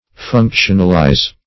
Search Result for " functionalize" : The Collaborative International Dictionary of English v.0.48: Functionalize \Func"tion*al*ize\, v. t. To assign to some function or office.